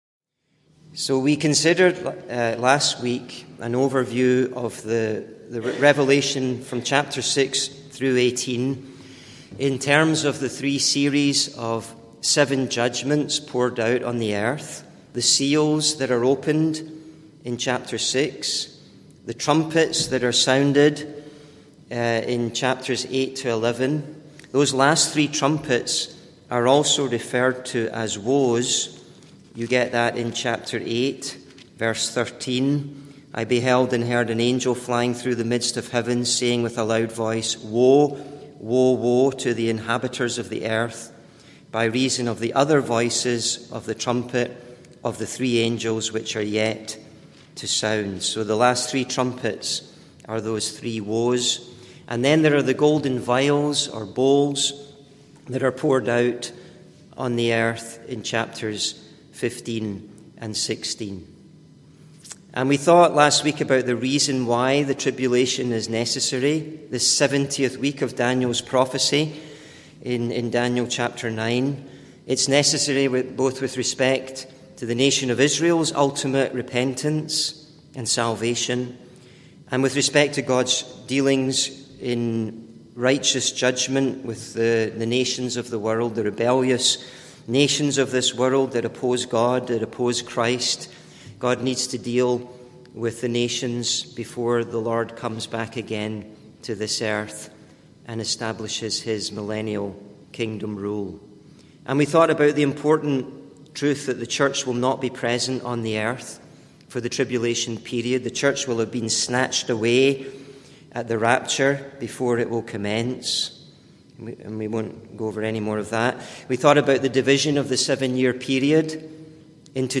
(Recorded in Parkview Street Gospel Hall, Winnipeg, MB, Canada on 13th Oct 2024) Sermon series: The 7-Sealed Scroll The
(Recorded in Parkview Street Gospel Hall, Winnipeg, MB, Canada on 13th Oct 2024)